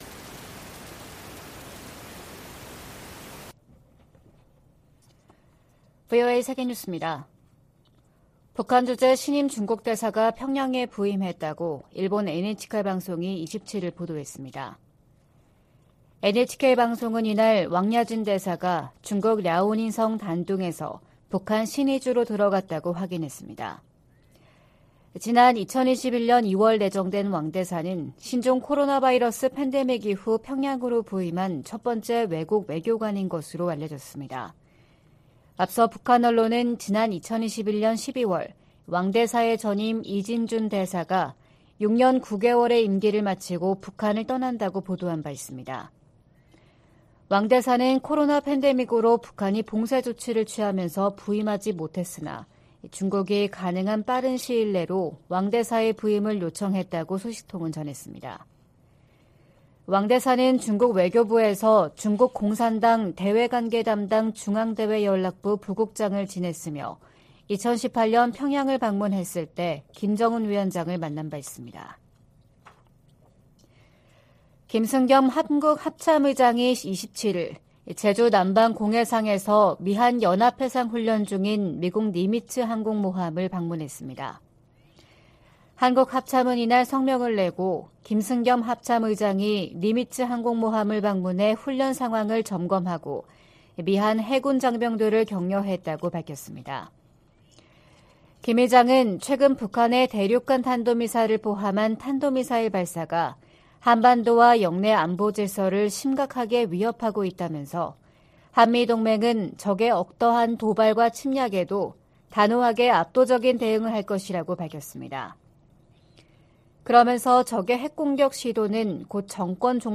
VOA 한국어 '출발 뉴스 쇼', 2023년 3월 28일 방송입니다. 북한이 또 다시 단거리 탄도미사일(SRBM) 두 발을 동해상으로 발사했습니다. 미 국방부는 북한의 수중 핵폭발 시험에 대해 우려를 나타내고, 한국과 다양한 훈련을 계속 수행할 것이라고 밝혔습니다. 미 공화당 중진 상원의원이 한국에 핵무기를 재배치하는 방안을 고려해야 한다고 주장했습니다.